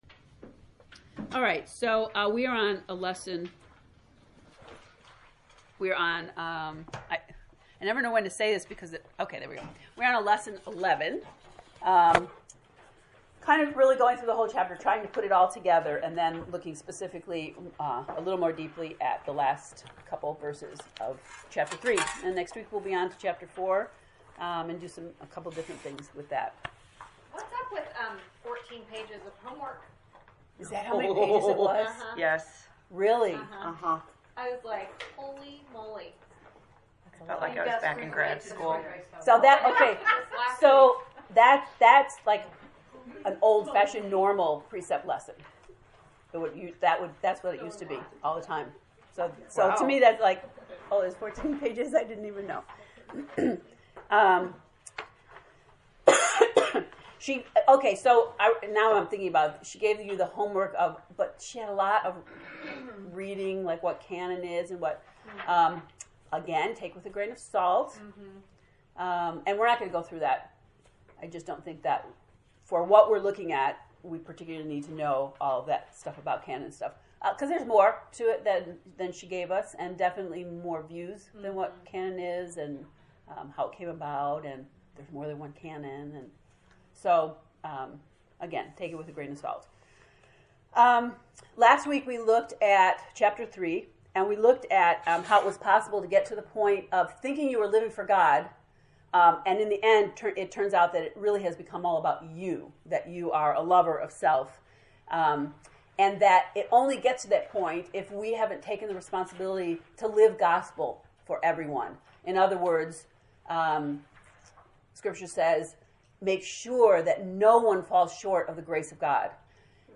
To listen to the lecture from lesson 11, “Kindling His Voice”, click below: